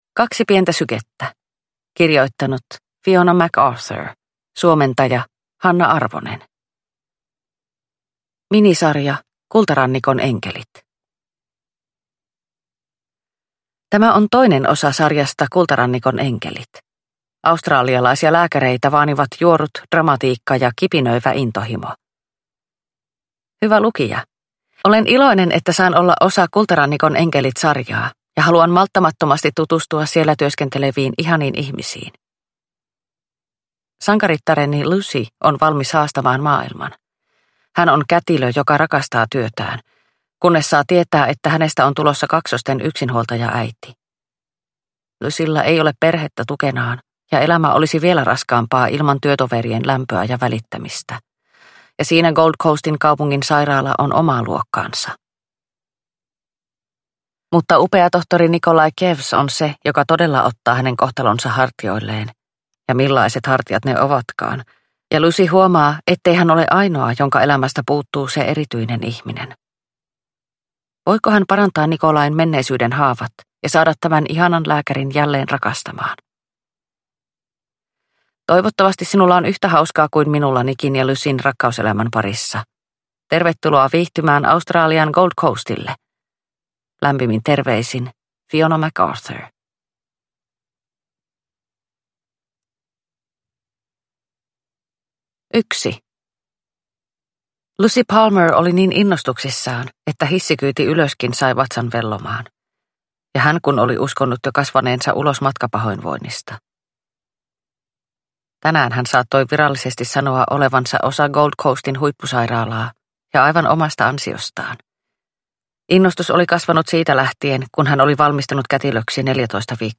Kaksi pientä sykettä – Ljudbok – Laddas ner